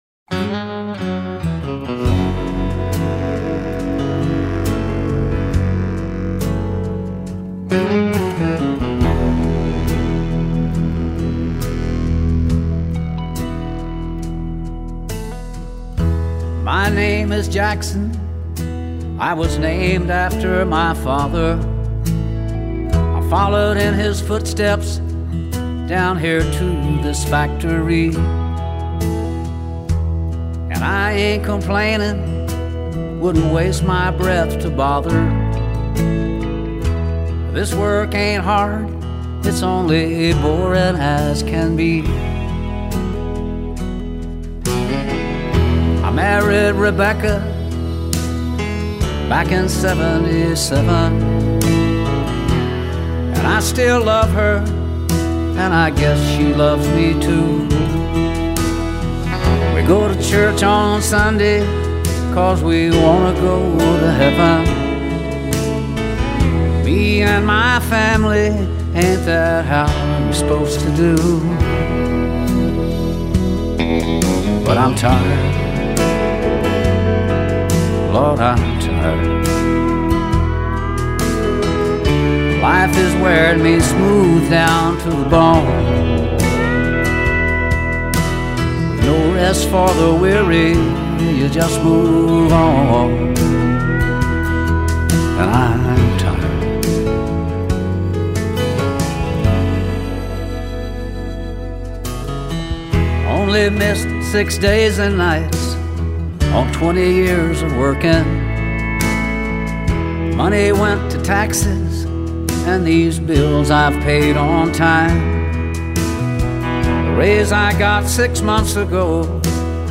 专辑类别：Country
耐聽的鄉村歌曲,輕鬆的旋律,開啟了一天愉快的心情,感謝!
很温暖的声线,谢谢